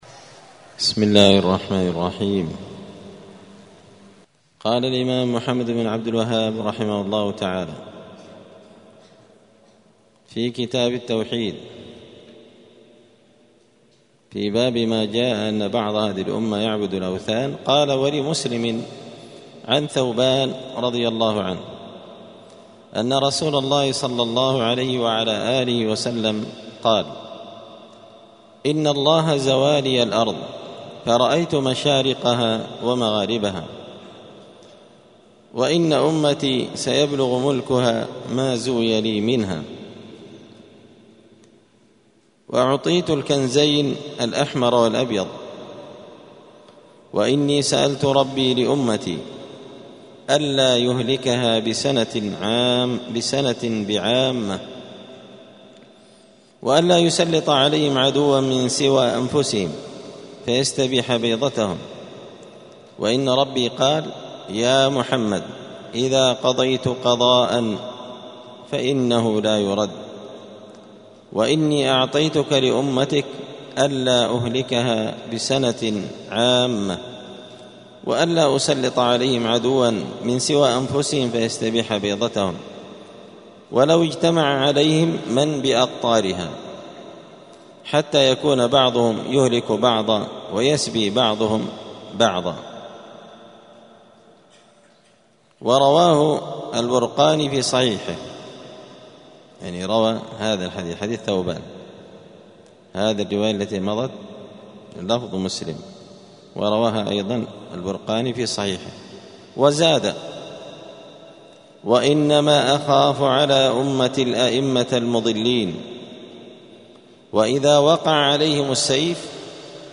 دار الحديث السلفية بمسجد الفرقان قشن المهرة اليمن
*الدرس الخامس والستون (65) {باب ماجاء أن بعض هذه الأمة يعبد الأوثان}*